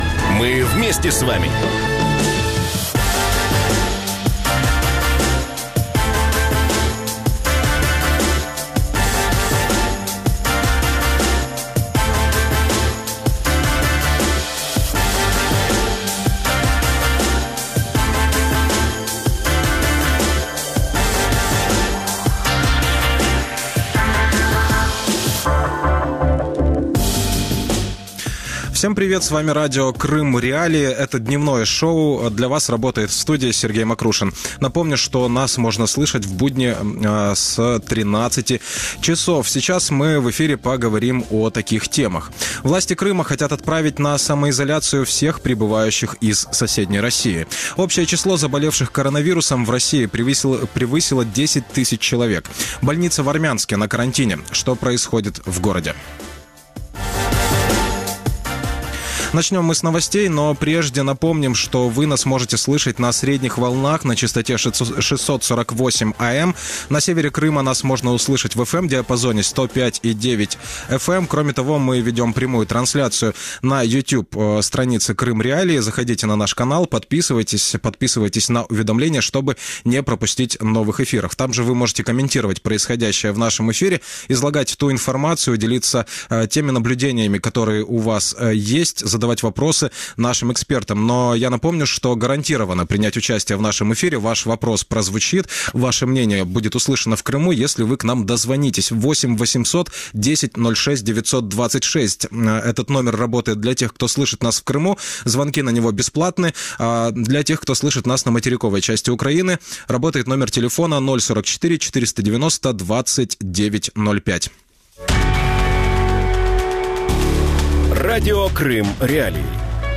Коронавирус в Армянске | Дневное ток-шоу